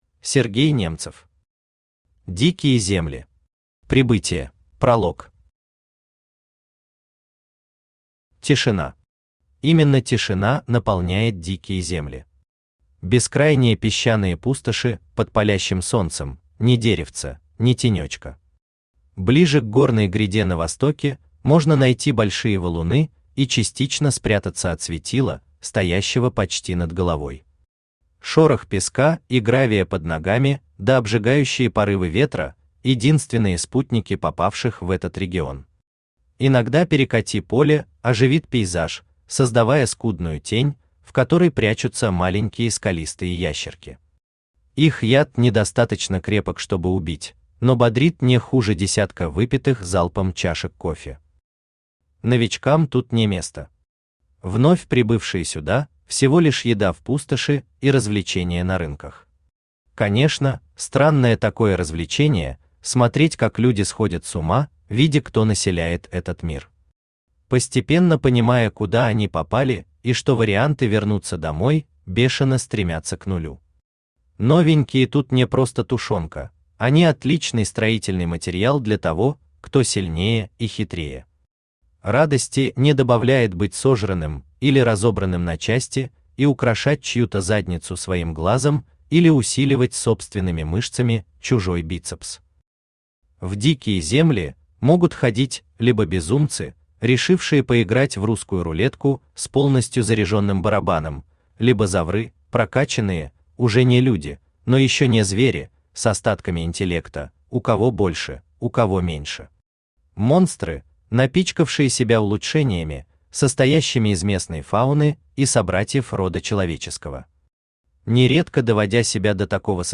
Прибытие Автор Сергей Немцев Читает аудиокнигу Авточтец ЛитРес.